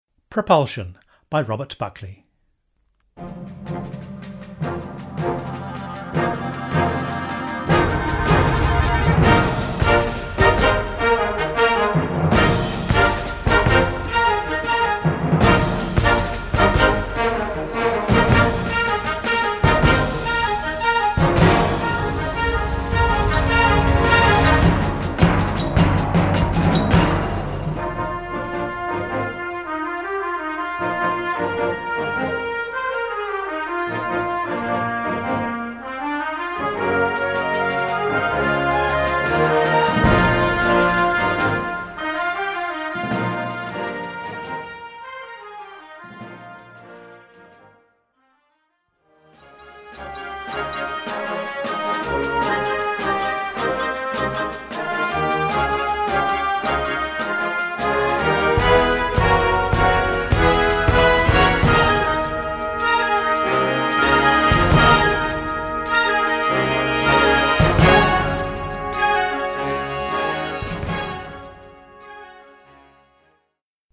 Category: OPENERS - Grade 3.0